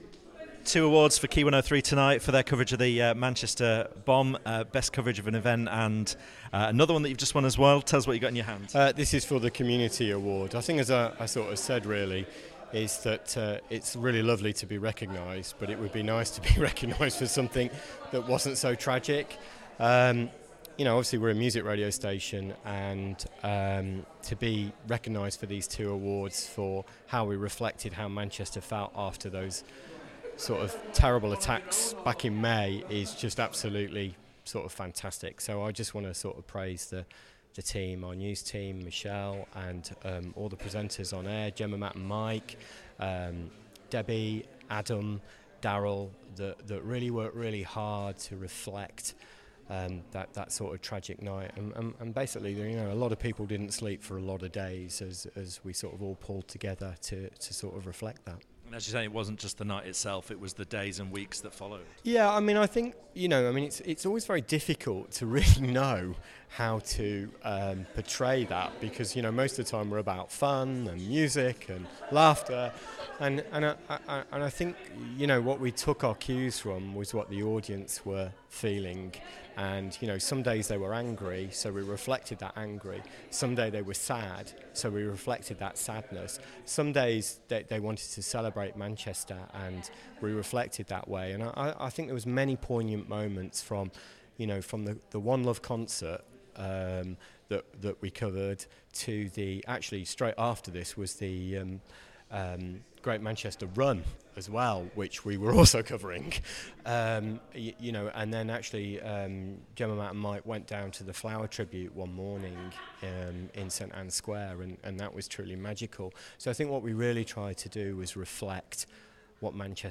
An episode by RadioToday Live Interviews